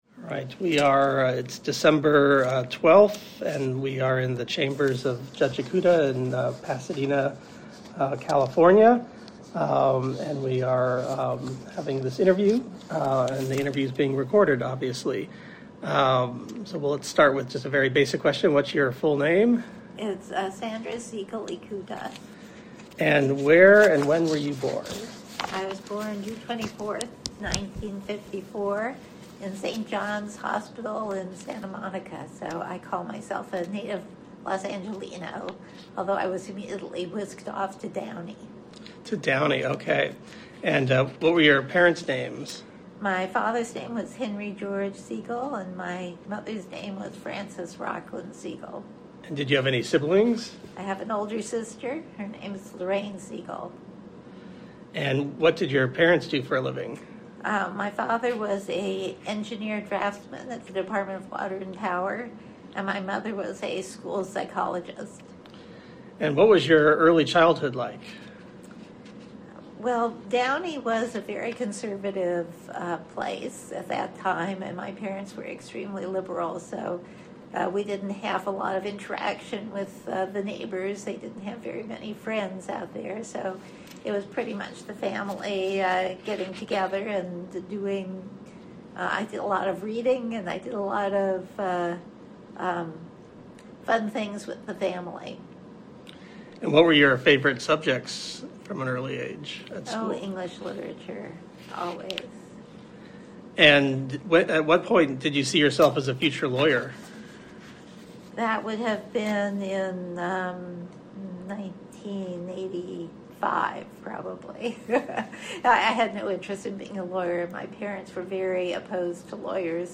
Judge Sandra S. Ikuta's Interview Audio
Judge-Ikuta-Interview.mp3